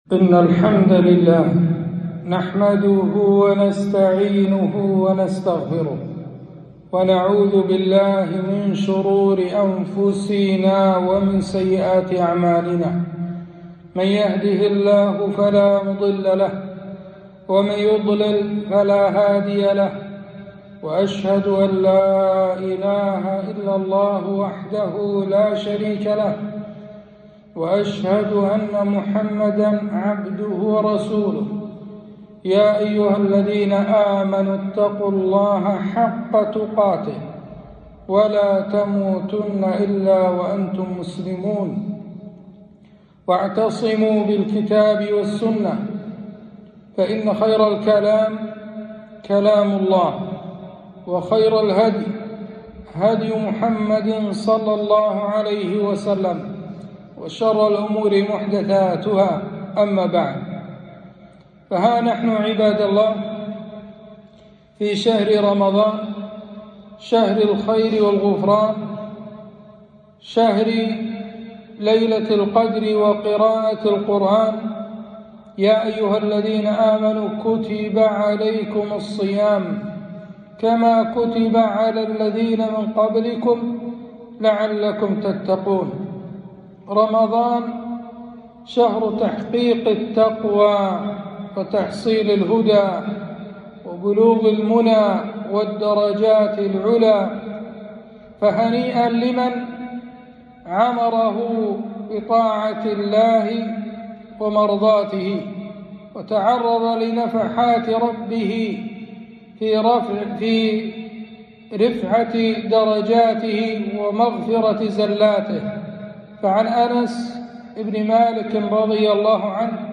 خطبة - هداية القرآن للتي هي أقوم